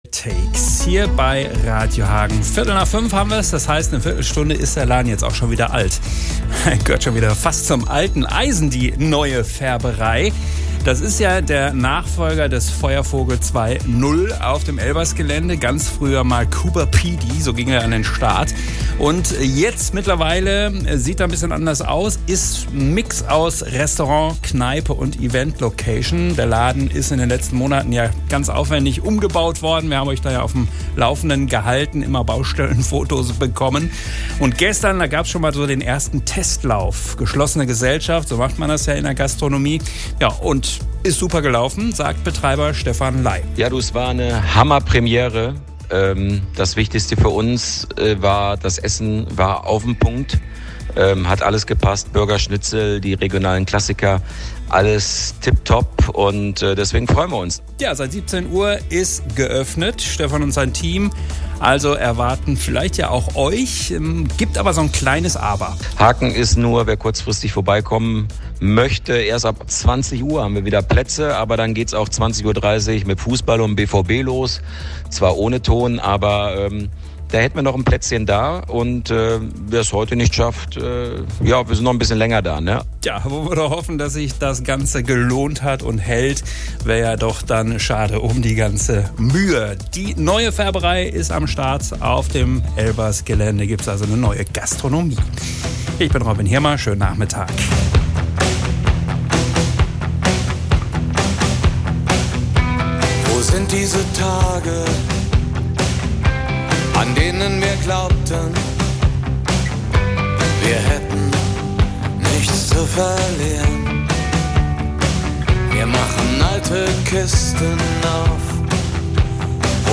Erst Coober Pedy, dann Feuervogel 2.0 - jetzt ist die Neue Färberei auf dem Elbersgelände geöffnet... Hier ein kleiner Stimmungsbericht!